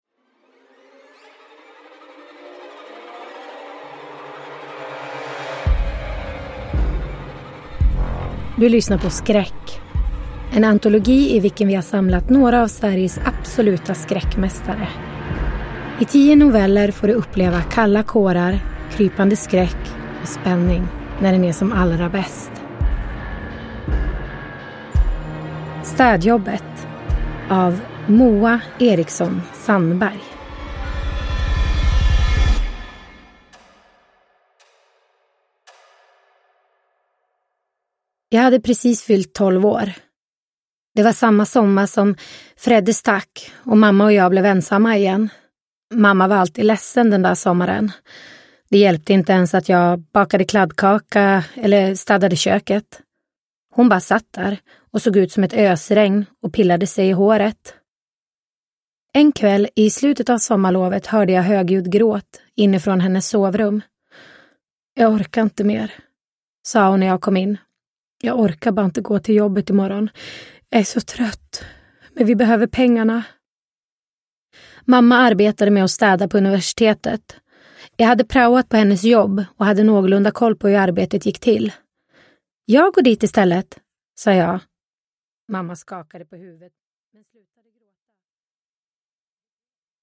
I antologin Skräck har vi samlat några av Sveriges absoluta skräckmästare. I tio noveller får du uppleva kalla kårar, krypande skräck och spänning när den är som allra bäst. Skriven av Moa Eriksson Sandberg och inläst av Emma Broomé.